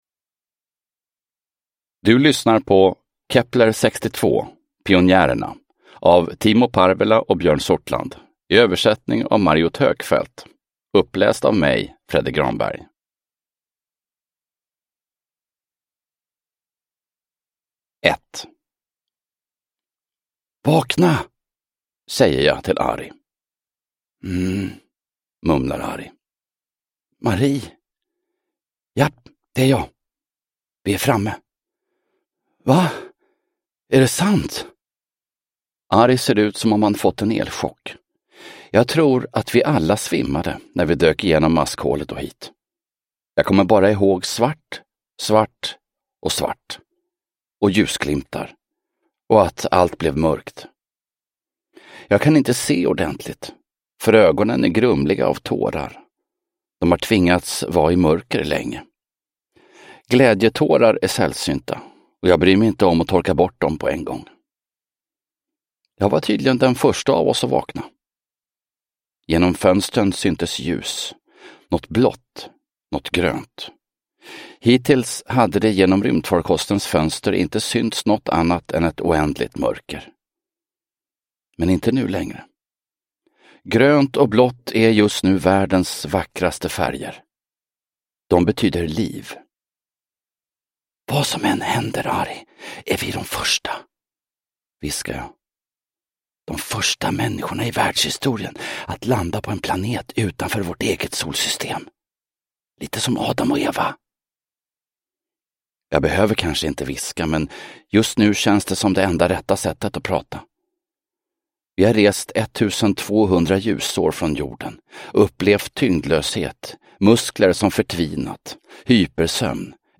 Pionjärerna – Ljudbok – Laddas ner